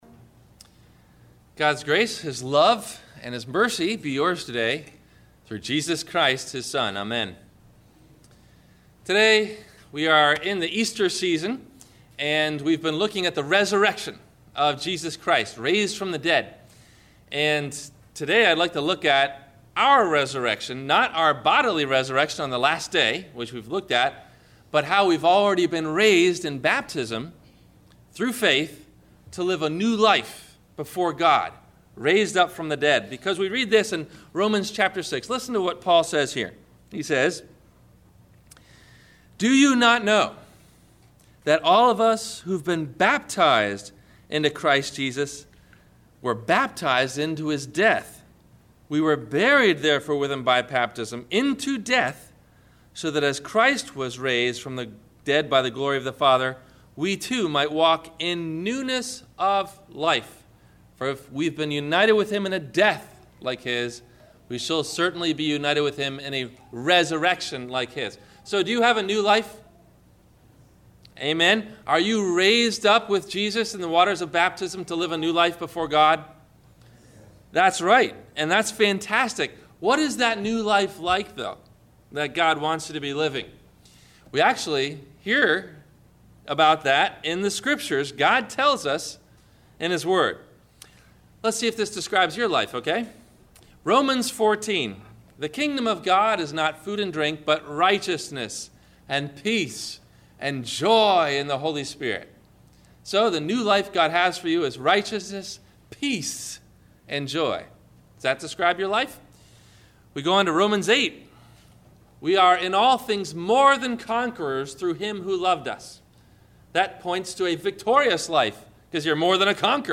Two Lives, Two Houses, Two Destinies – Sermon – December 30 2012